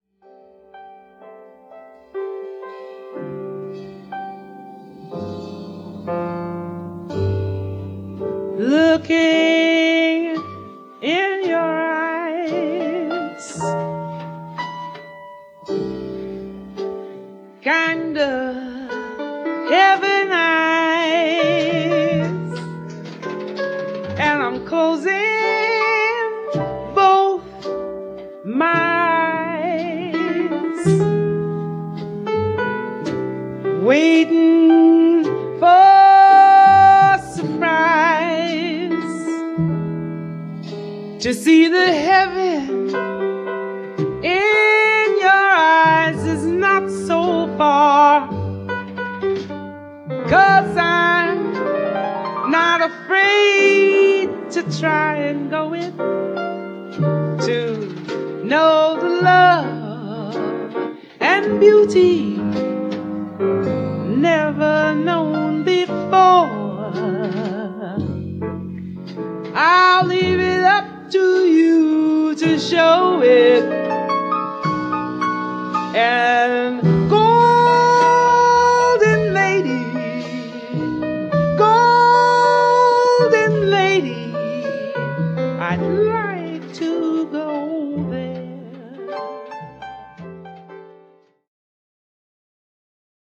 vocals
piano
bass
drums